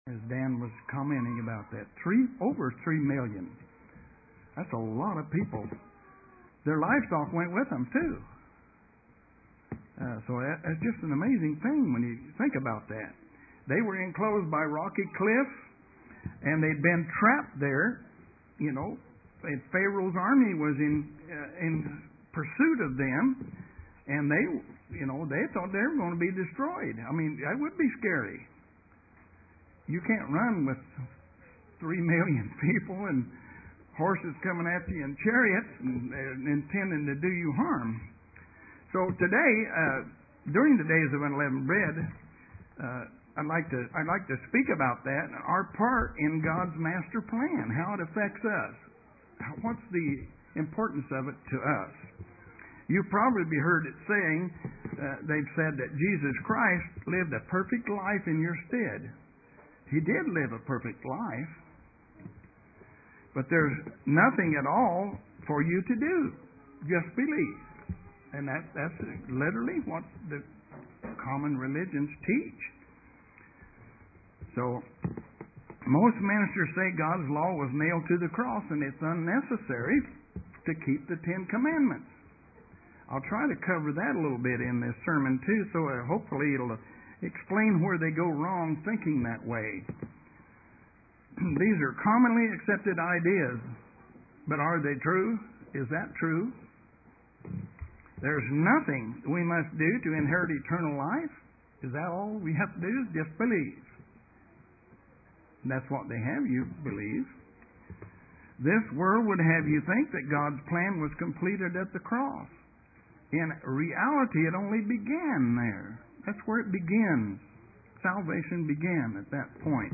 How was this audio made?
Is just believing in Christ all we have to do to be saved? This message was given on the First Day of Unleavened Bread.